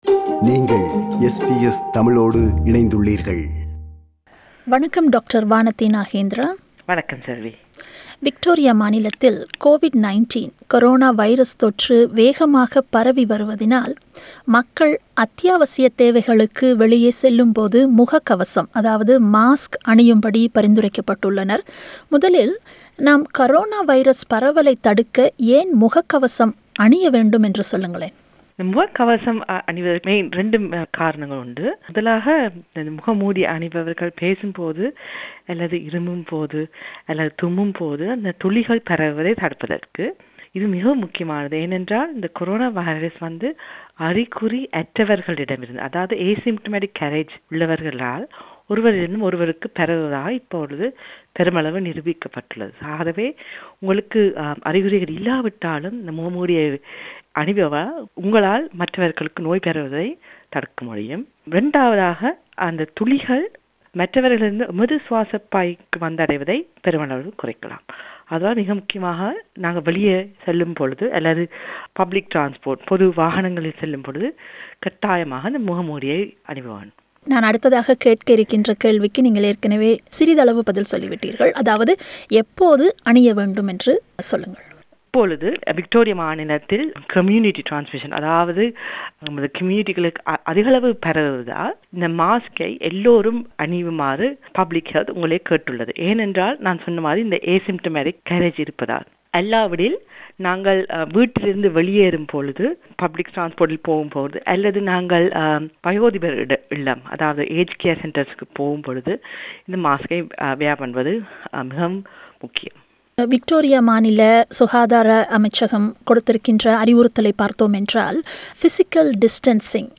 Along with that two residents from Melbourne shares their opinion about wearing mask.